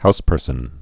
(houspûrsən)